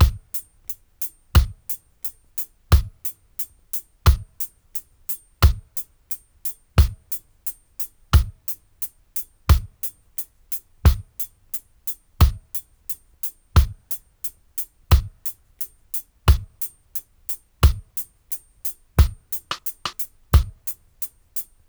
88-DRY-03.wav